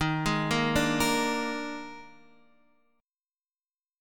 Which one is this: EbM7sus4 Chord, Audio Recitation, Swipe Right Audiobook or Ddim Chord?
EbM7sus4 Chord